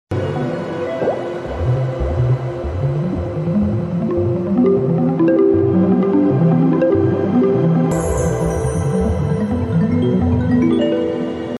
Funny interactive ball game room